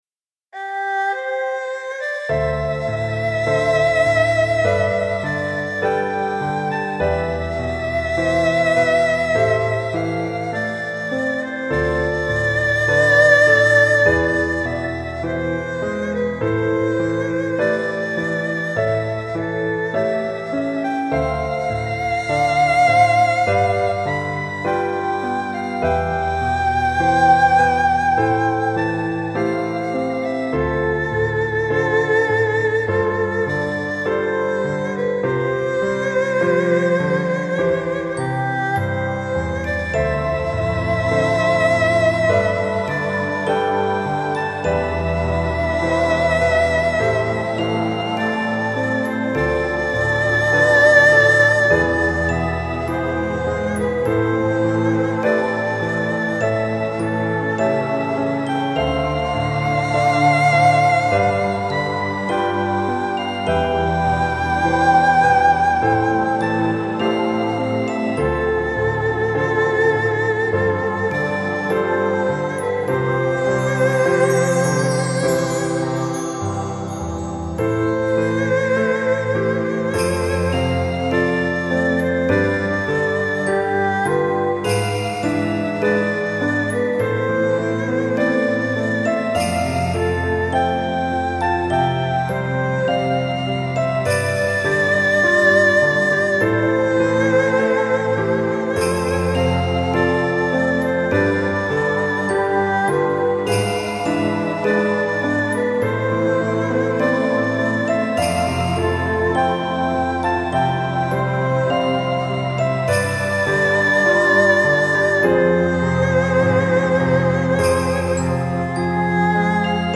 2017.06 | 癒し | ニューエイジ | 3分05秒/2.82 MB